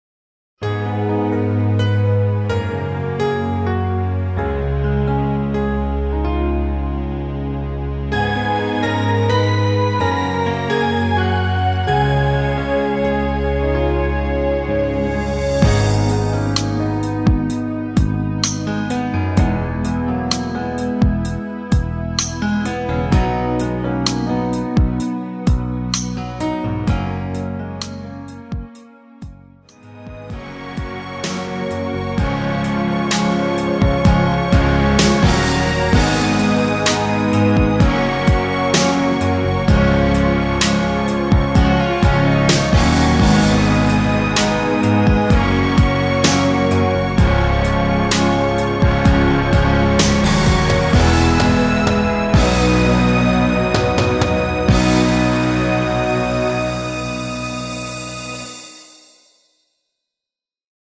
엔딩이 페이드 아웃이라 라이브 하시기 좋게 엔딩을 만들어 놓았습니다.
Abm
앞부분30초, 뒷부분30초씩 편집해서 올려 드리고 있습니다.